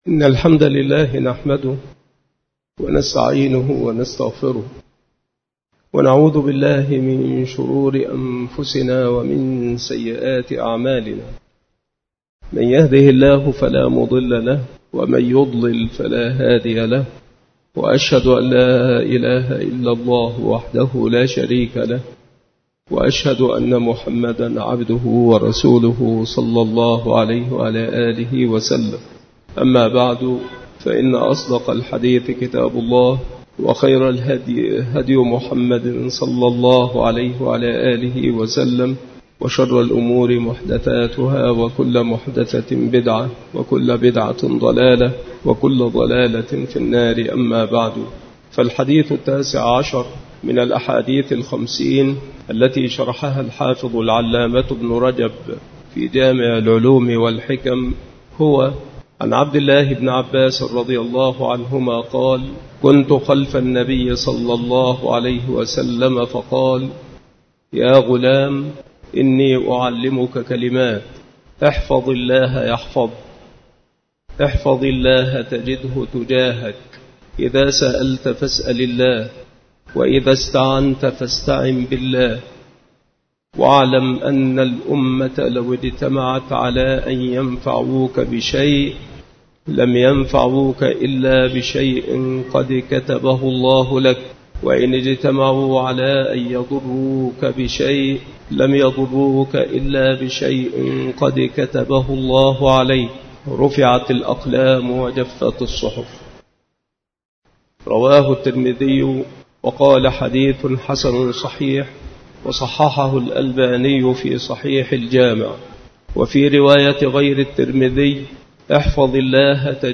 شروح الحديث
مكان إلقاء هذه المحاضرة بالمسجد الشرقي بسبك الأحد - أشمون - محافظة المنوفية - مصر